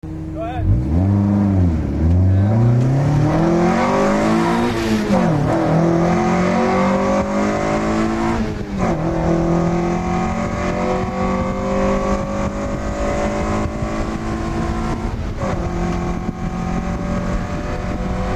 SIRaccel2.mp3